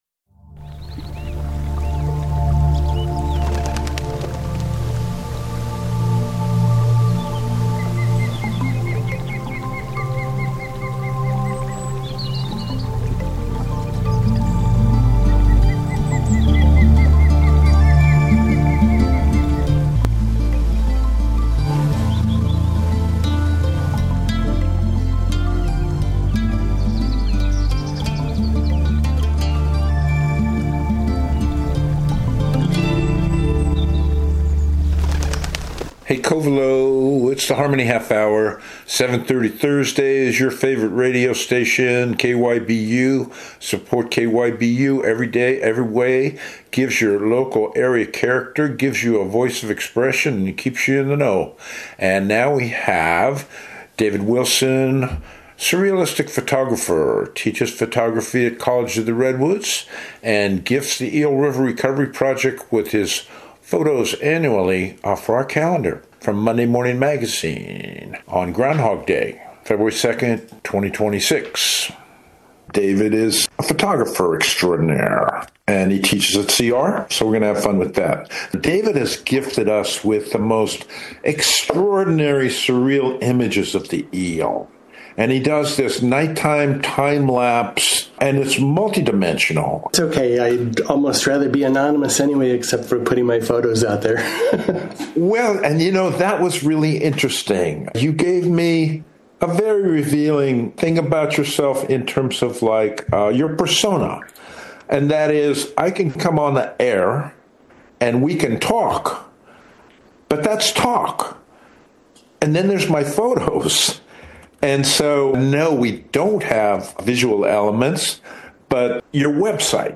KMUD Interview, February 2, 2026.